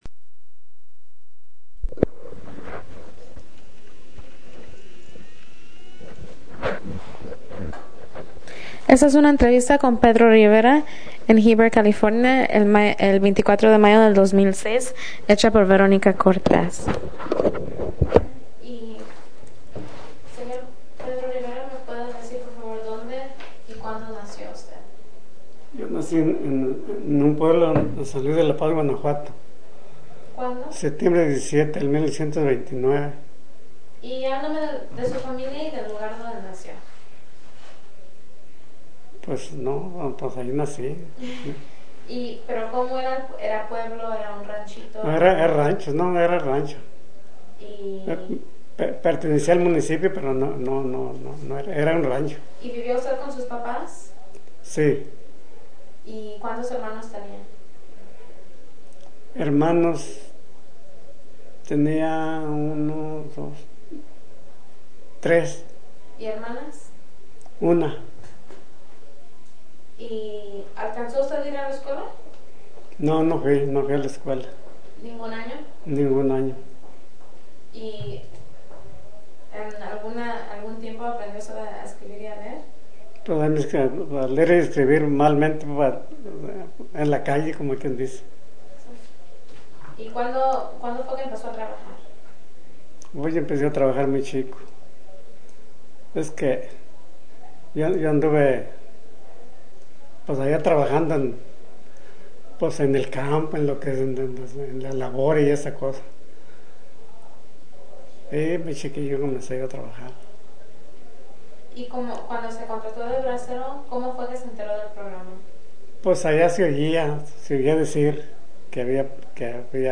Original Format Mini disc
Location Heber, CA